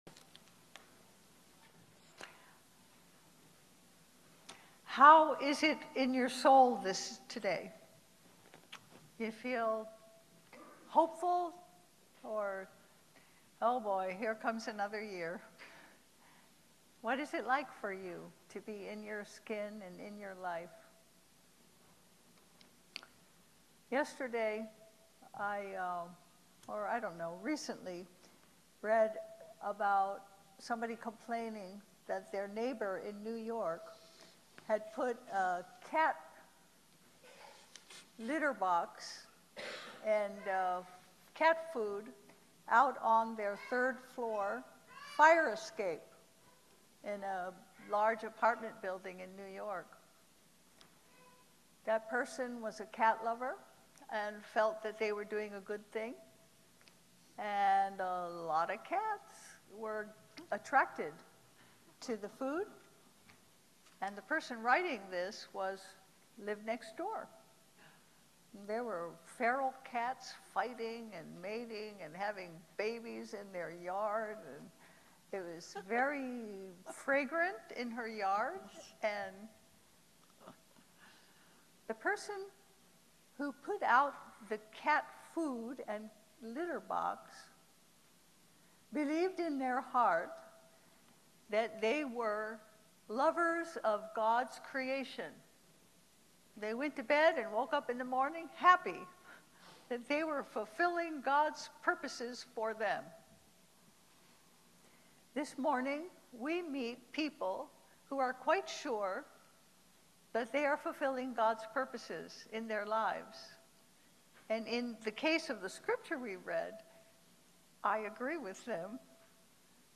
Passage: Luke 1:26-38, 46-55 Service Type: Sunday Service Download Files Bulletin « The Time Came What Kind of Baptism Did You Receive?